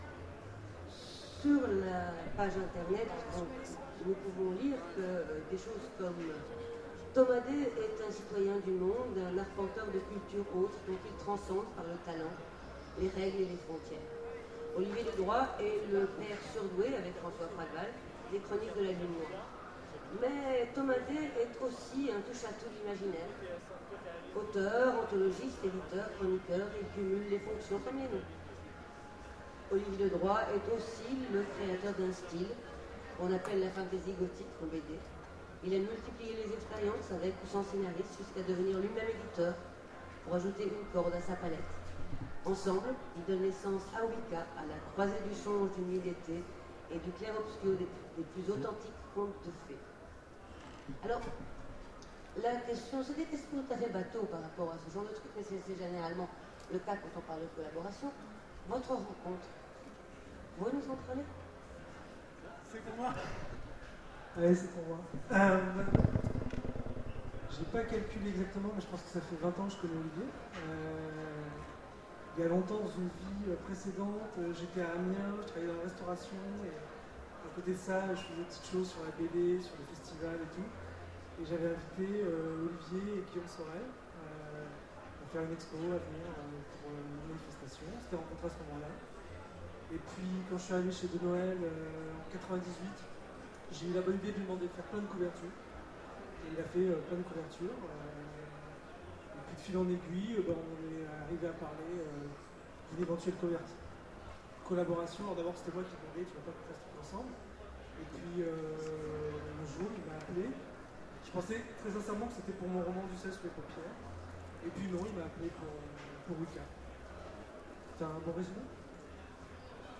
Utopiales 2014 : Rencontre avec Thomas Day et Olivier Ledroit
Rencontre avec un auteur Conférence